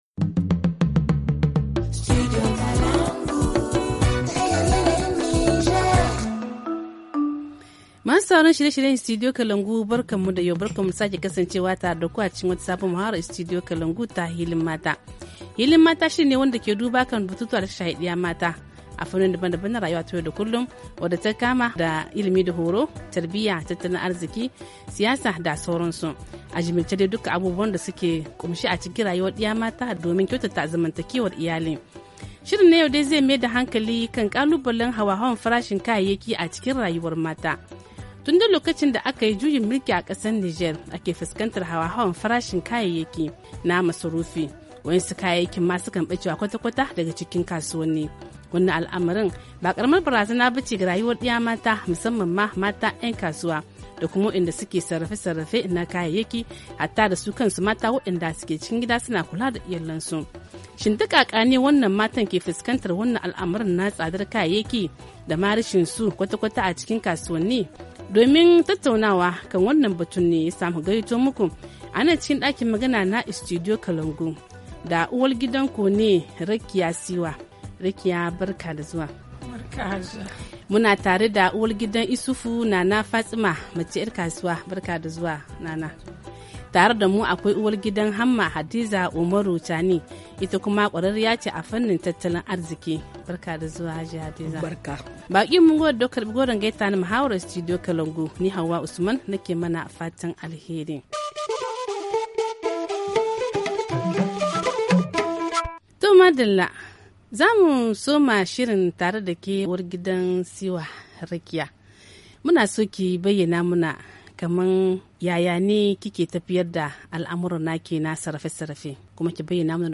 HA Le forum en haoussa Télécharger le forum ici.